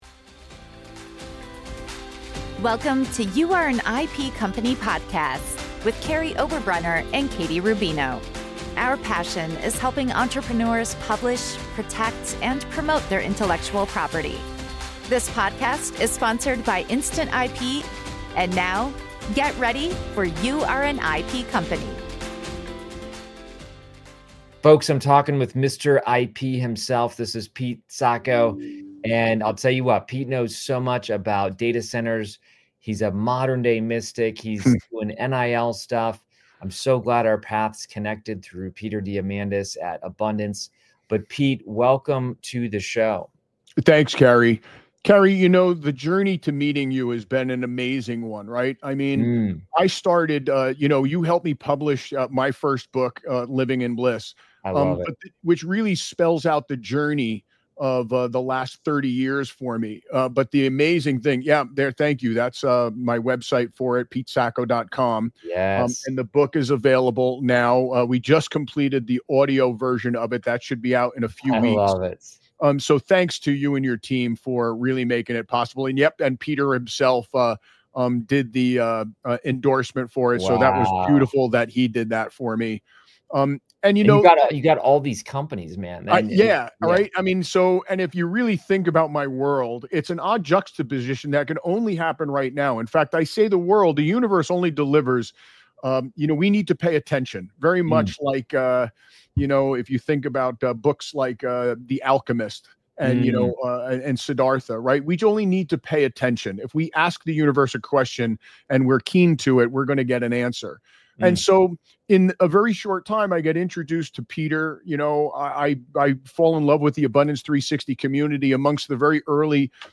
Protecting Ideas in the Age of AI: A Conversation